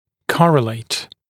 [‘kɔrəleɪt][‘корэлэйт]соотносить(ся), коррелировать